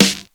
Dusty Snare 03.wav